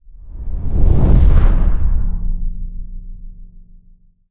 cinematic_deep_bass_pass_whoosh_05.wav